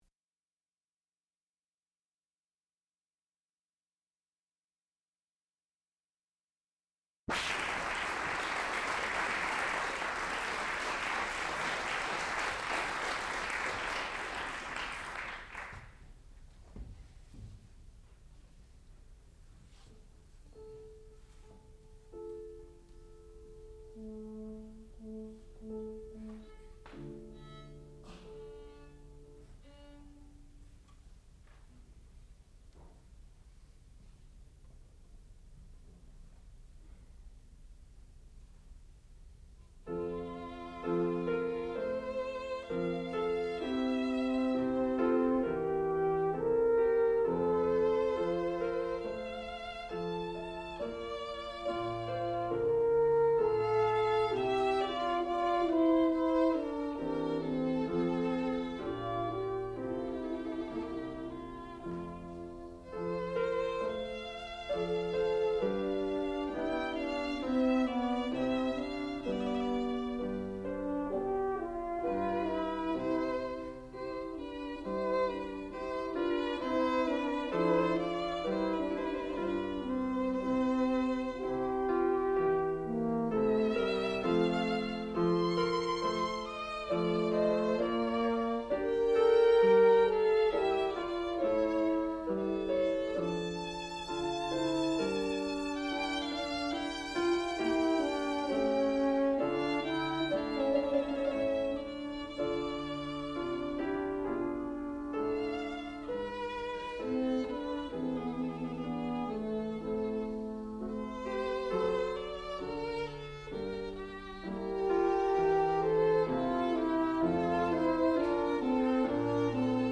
sound recording-musical
classical music
violin
piano
horn